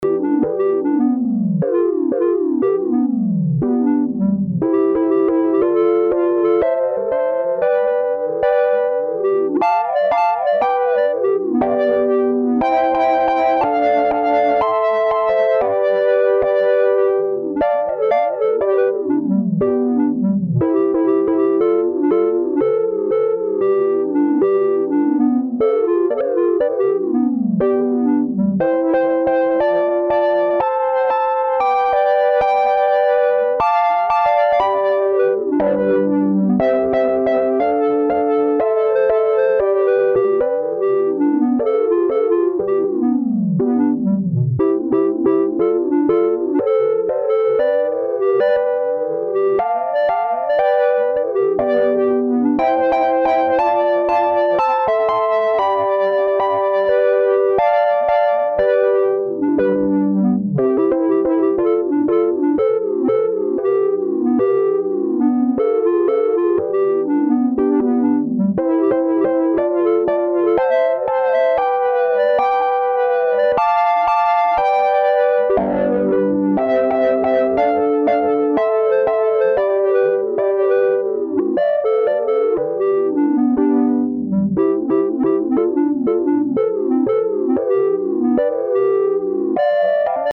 instruo harmonaigh deriving chords from a single cv sequence (and then with voicing modulation... and other modulation...)